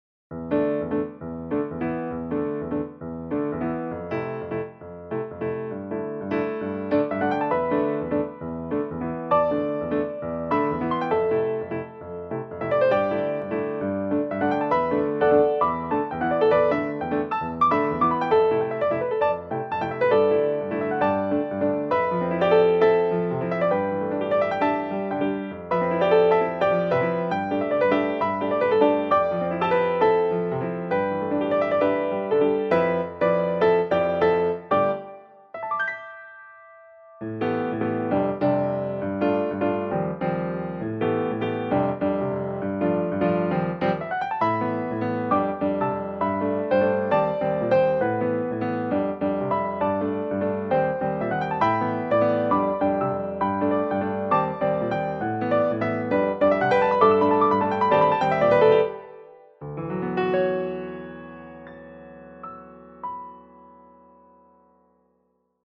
この中で 「私に触れないで」 という花言葉をテーマにしてあります。 イメージとしては「迷路の中を誰かに追いかけられる」的な感じですね＾＾ ホラー的な要素ではなく、もう少しかわいい感じですね。 後半あえて不安的なイメージも含めた感じでメロディーに対して 下に５度の音を混ぜています。
リアルタイムでの鍵盤打ち込み+クオンタイズ を利用して時間短縮をしています。